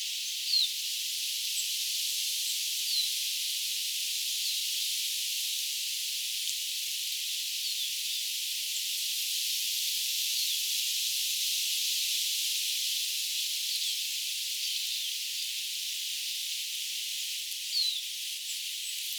että ne toistivat tiiy-ääntä.
tiiy-vihervarpusia, 2
tiiy-vihervarpusia_saaressa.mp3